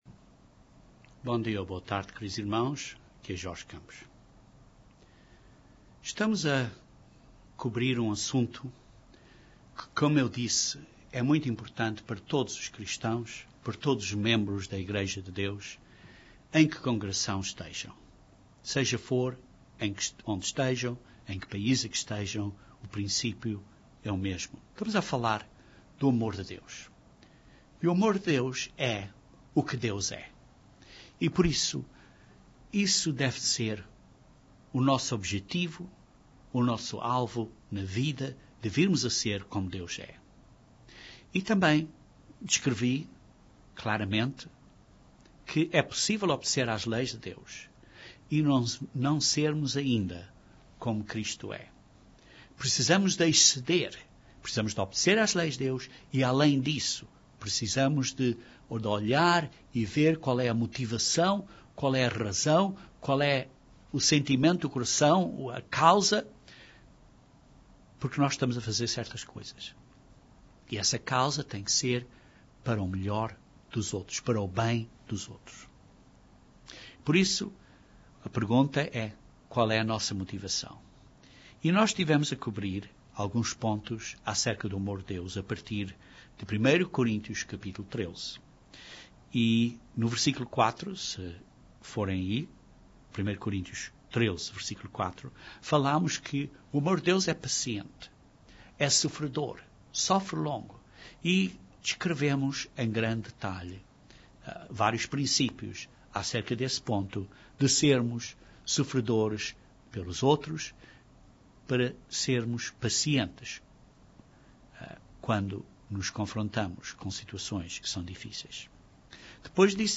É possível obedecer às Leis de Deus e não seres como Cristo é? Este sermão continua este estudo do Amor de Deus e abrange dois princípios importantes adicionais para manifestarmos o verdadeiro Amor de Deus.